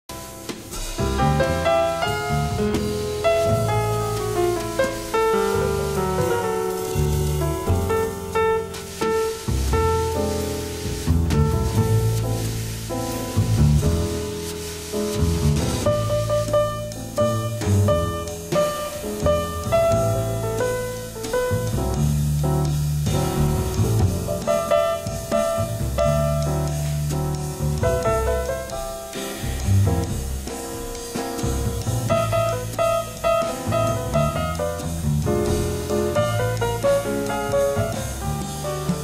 LIVE AT NORTH SEA JAZZ FESTIVAL, THE NETHERLAND
SOUNDBOARD RECORDING